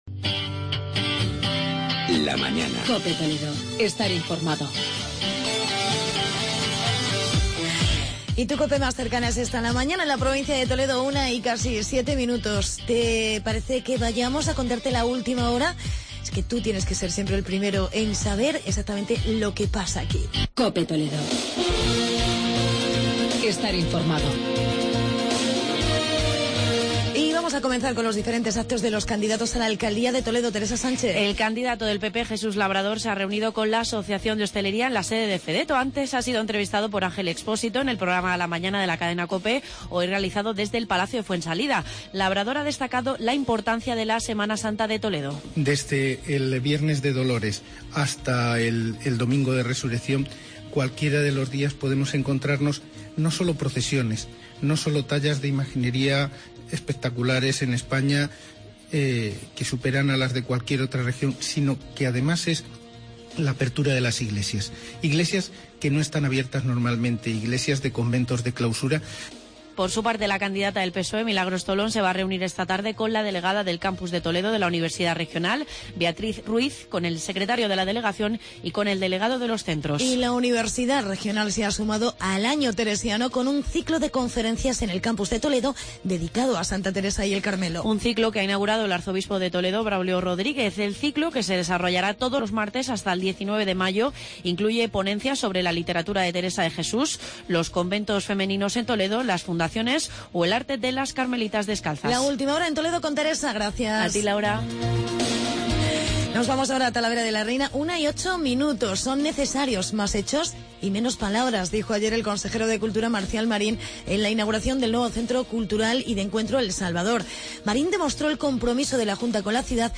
Entrevista al alcalde Jaime Ramos sobre "El Salvador"